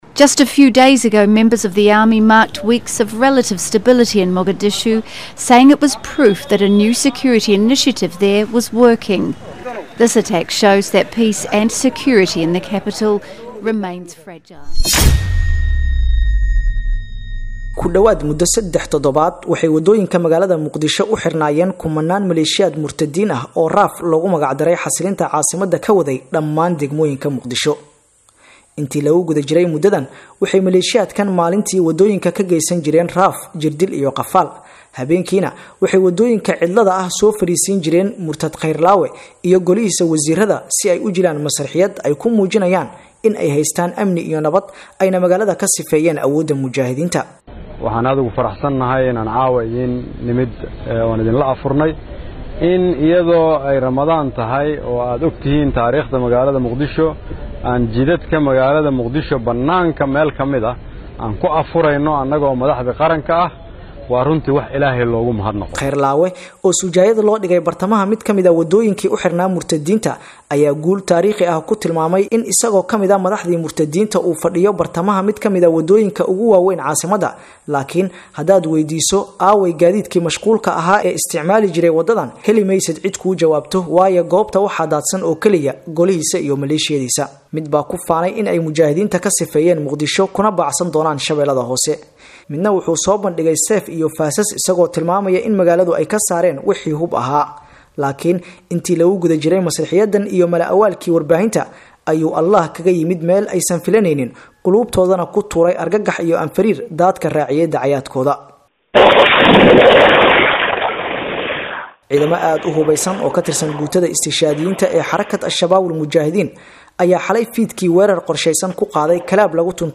Dhageyso Alshabaabkii Weeraray hotelka Boosh oo ka hadlaya Hoteelka Dhaxdiisa